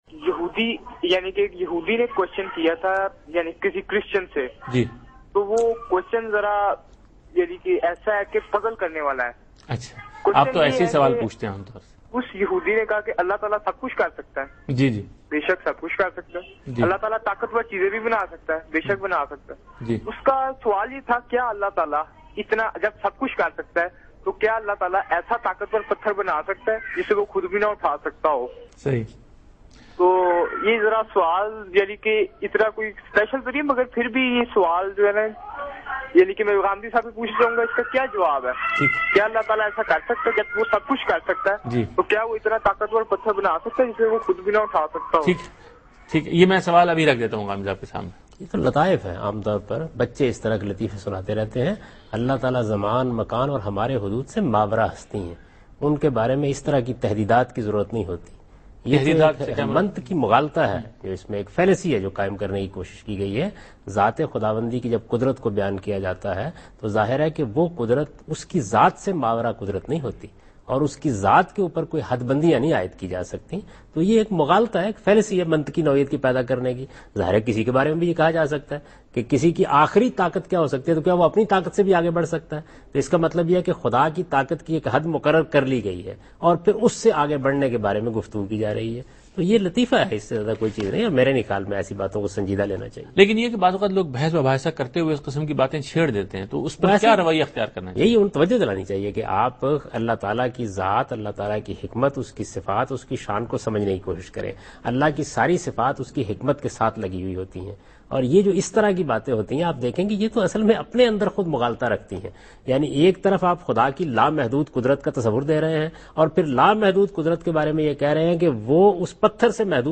Javed Ahmad Ghamidi answers a question about "Power of God" in program Deen o Daanish on Dunya News.
جاوید احمد غامدی دنیا نیوز کے پروگرام دین و دانش میں خدا کی طاقت سے متعلق ایک سوال کا جواب دے رہے ہیں۔